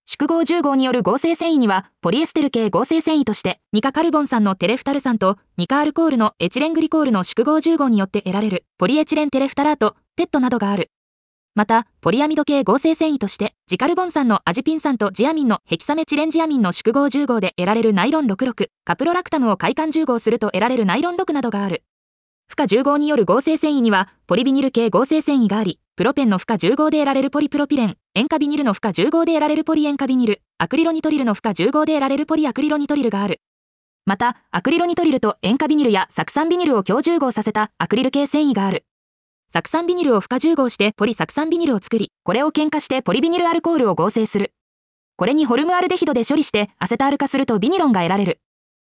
• 耳たこ音読では音声ファイルを再生して要点を音読します。